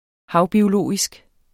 Udtale [ ˈhɑwbioˌloˀisg ]